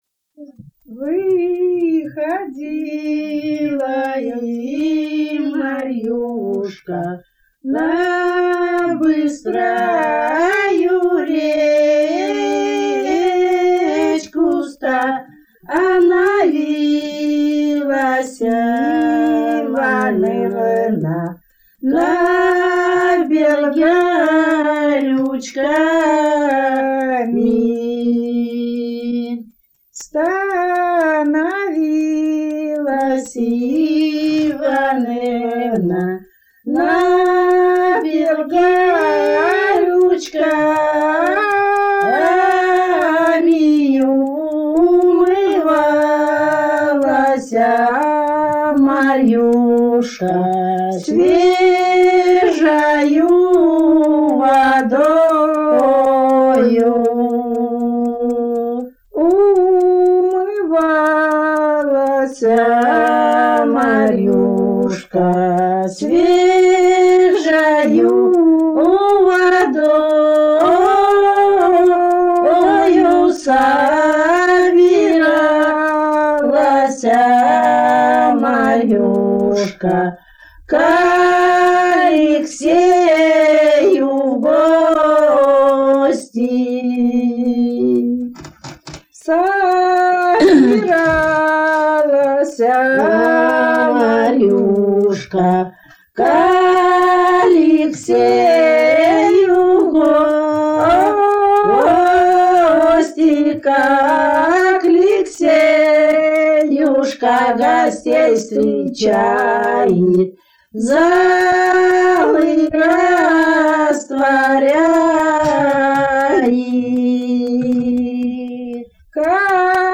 Народные песни Касимовского района Рязанской области «Выходила и Марьюшка», лирическая.